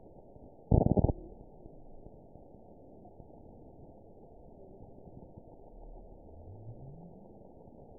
event 922355 date 12/30/24 time 02:32:29 GMT (11 months ago) score 9.66 location TSS-AB04 detected by nrw target species NRW annotations +NRW Spectrogram: Frequency (kHz) vs. Time (s) audio not available .wav